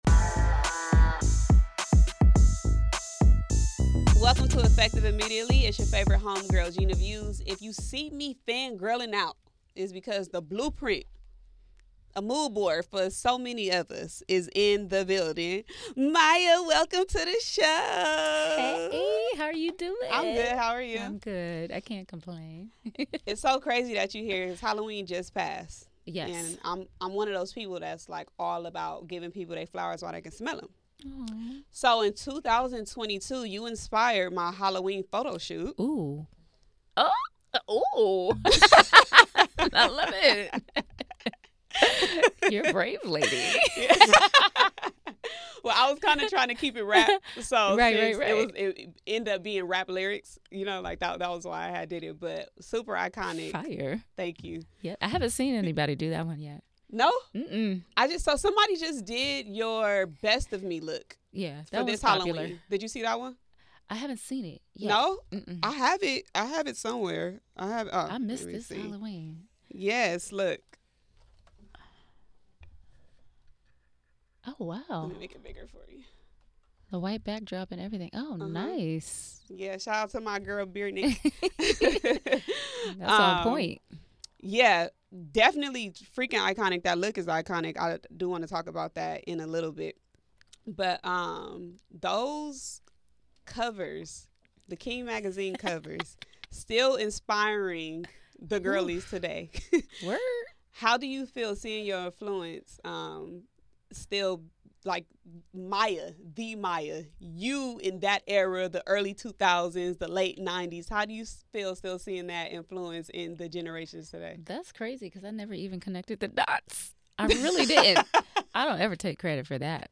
Mya On Legacy, Classic Records, Viral Jersey Dress, New Song, Album & MORE❗| Effective Immediately